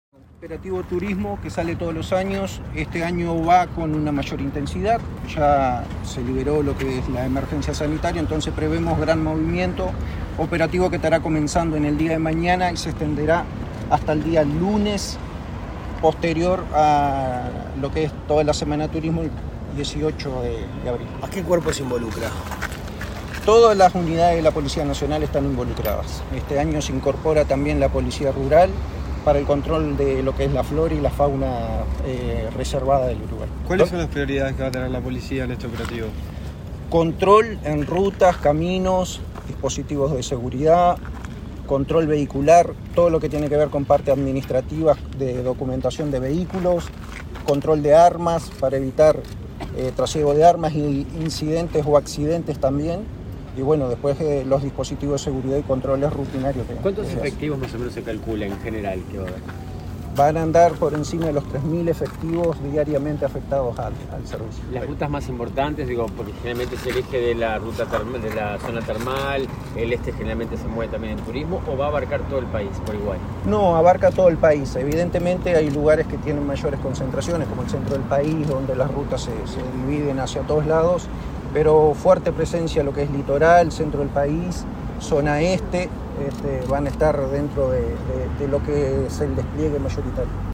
Declaraciones a la prensa del jefe del Estado Mayor general de la Policía, Richard Cabral
Con la presencia del ministro del Interior, Luis Alberto Heber, se realizó este jueves 7 el lanzamiento del operativo para Semana de Turismo, que comenzará el viernes 8 y se extenderá hasta el lunes 18 de abril. El jefe del Estado Mayor, general de la Policía, Richard Cabral, expresó a la prensa que se prevé mayor movimiento turístico.